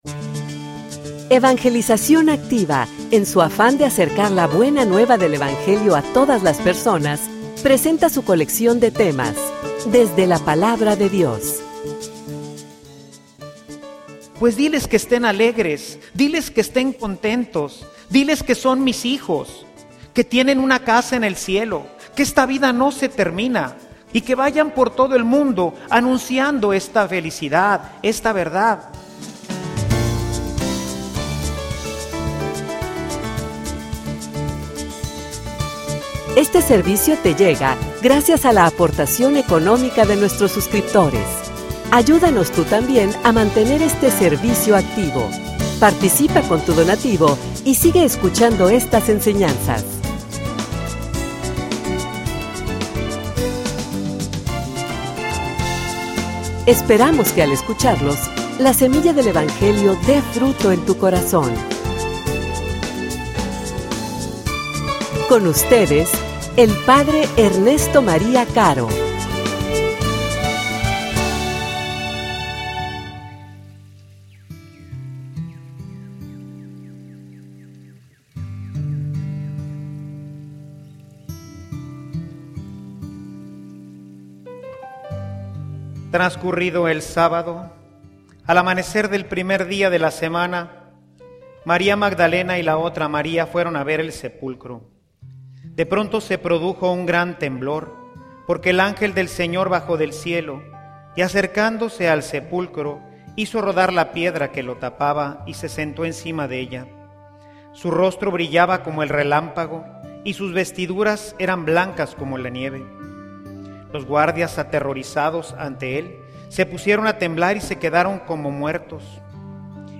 homilia_Diles_que_esten_alegres.mp3